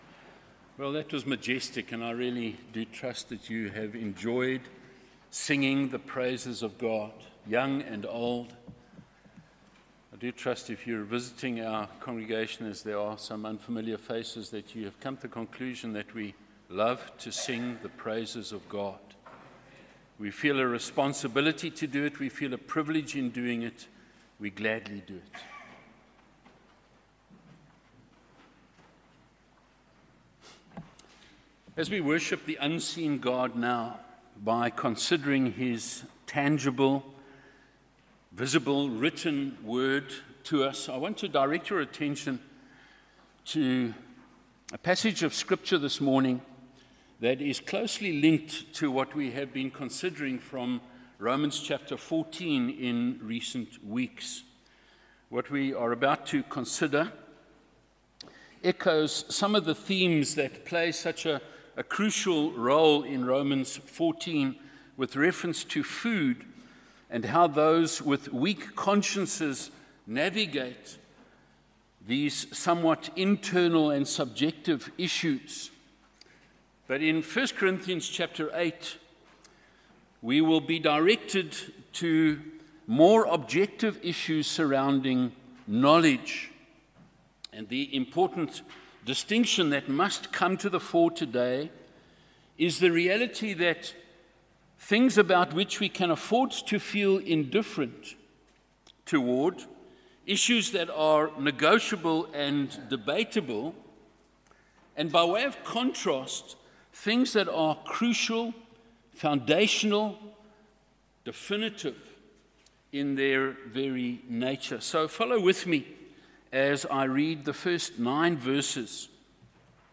Sermons under misc. are not part of a specific expositional or topical series.